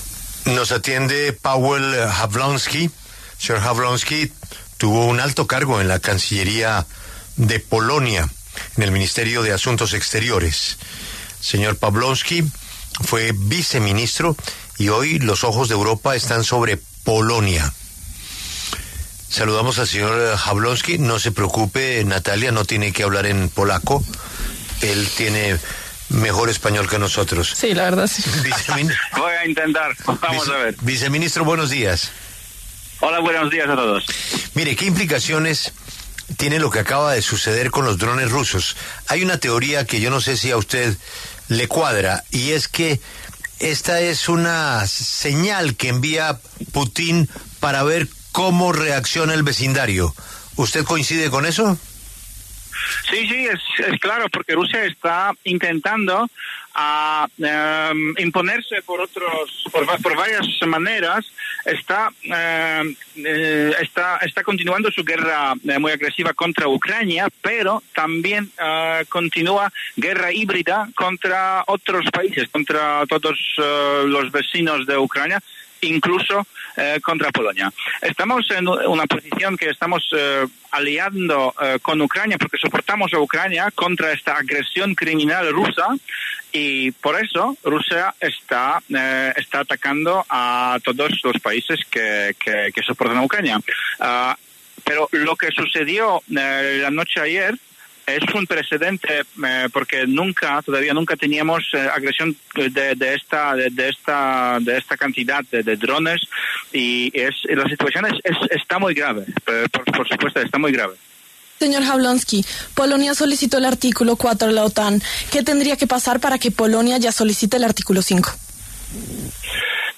Paweł Jabłoński, ex viceministro de Asuntos Exteriores de Polonia, conversó con Julio Sánchez Cristo para La W a propósito de la respuesta de su país a la “provocación” de Rusia con drones, cerrando parcialmente su espacio aéreo en la frontera este.